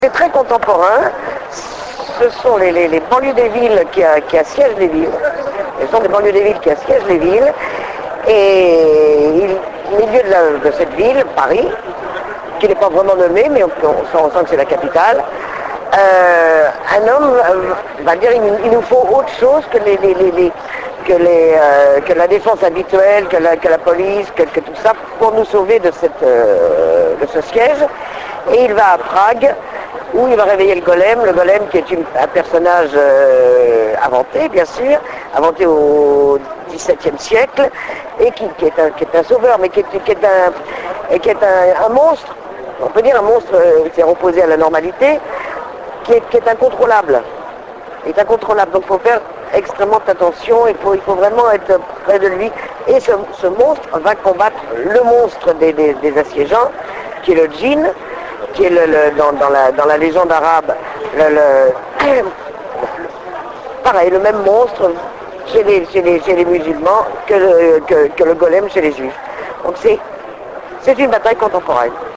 Interview Maud Tabachnik - Mai 2007